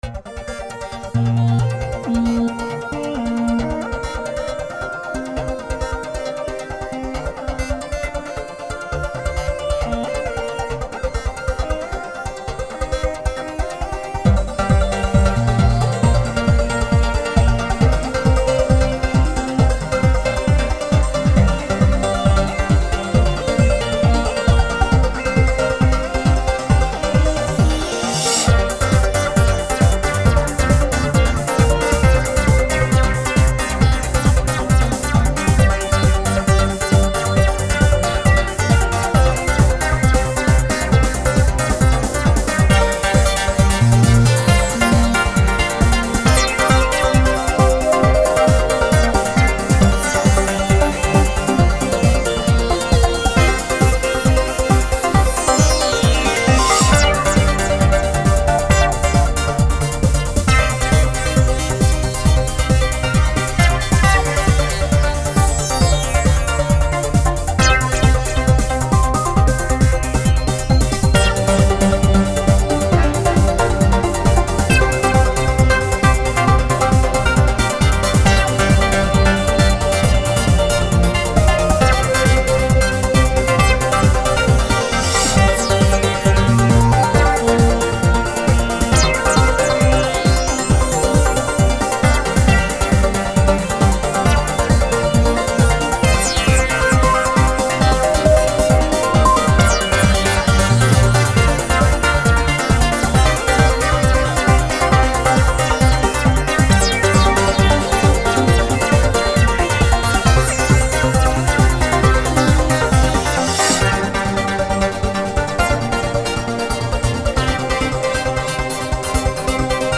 dance/electronic
Techno
Trance
Electro